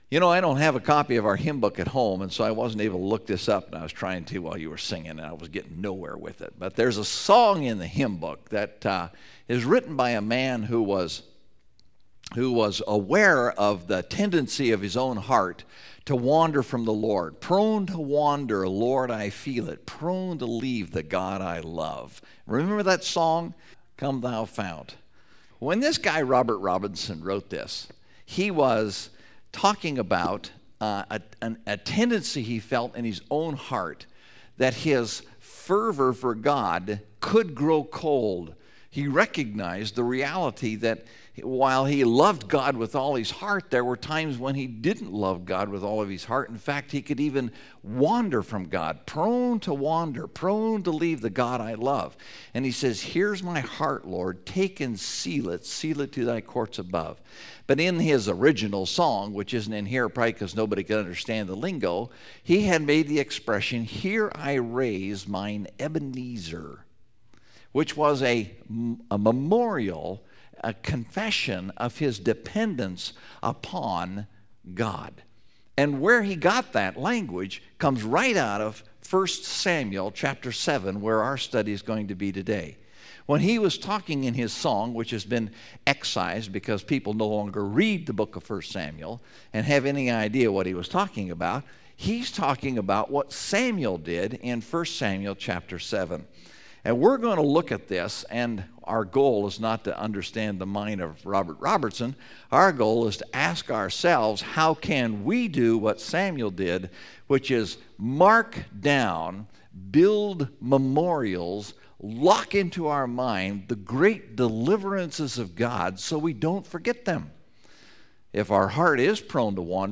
Your Own Ebenezer (1 Samuel 7:2-8:3) – Mountain View Baptist Church